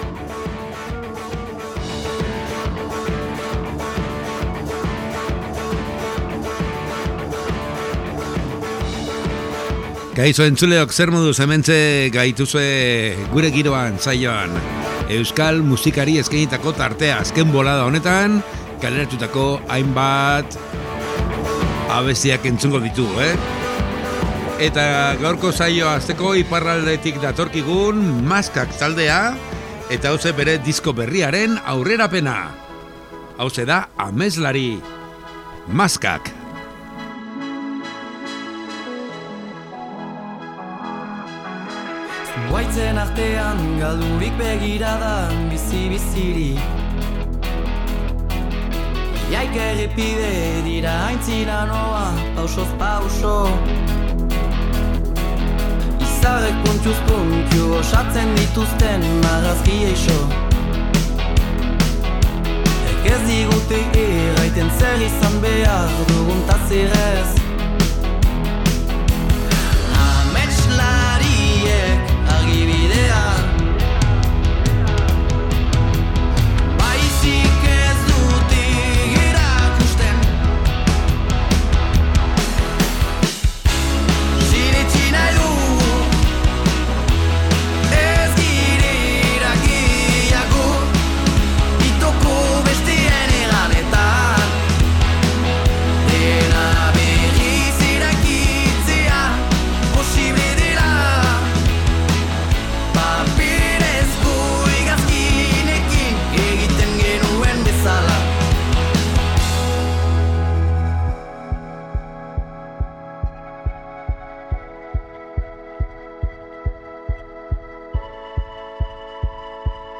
Gure giroan saioan estilo ezberdinetako musika eta azken nobeadeak entzuteko aukera.